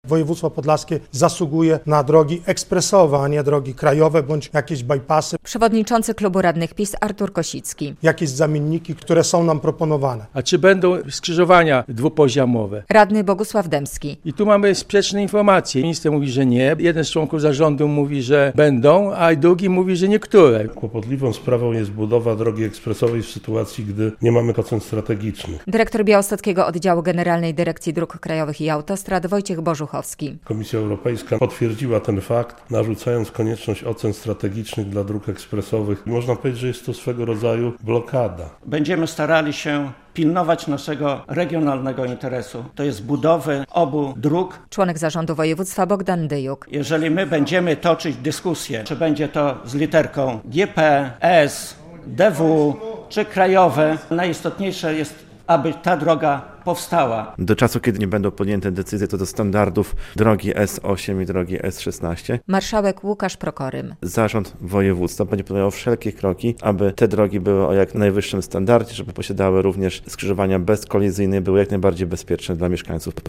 Tymi tematami zajmowali się we wtorek (18.02) podlascy radni na nadzwyczajnej sesji sejmiku.
relacja